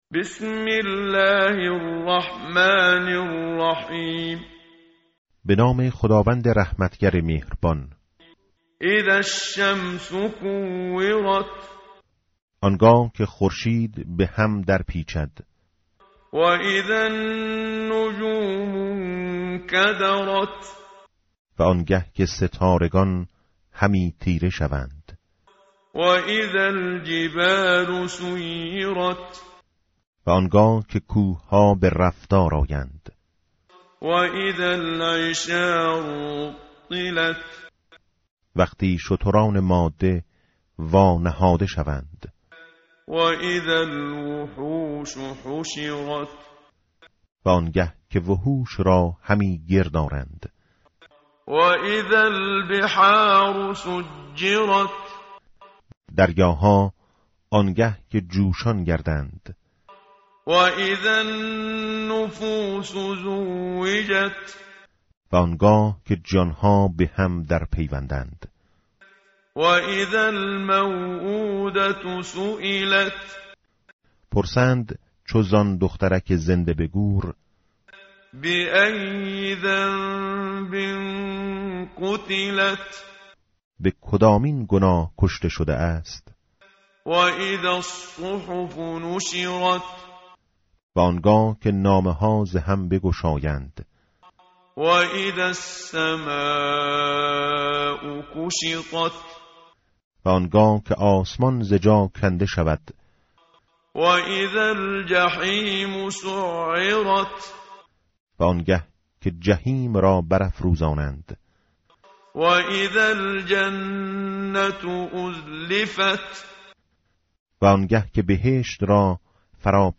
tartil_menshavi va tarjome_Page_586.mp3